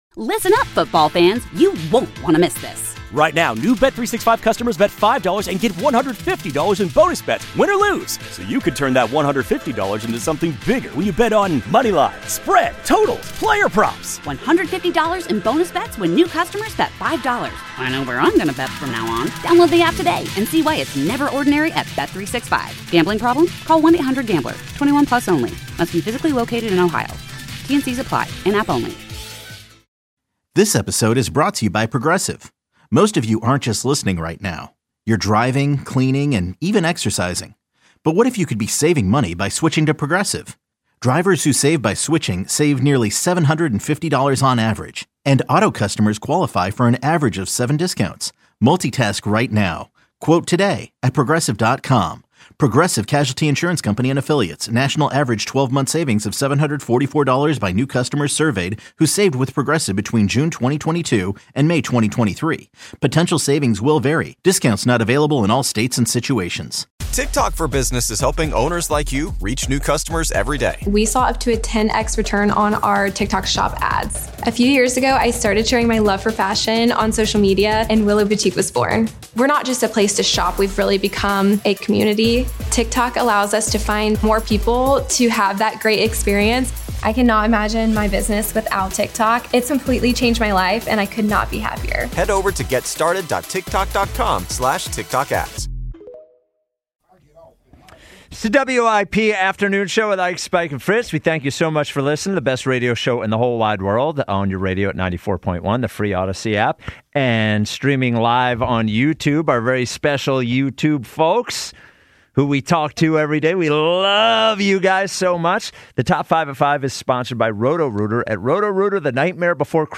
In hour 4, the WIP Afternoon Show are discussing annoying trends they are currently having for the top 5 at 5. Plus, the text line, your calls and more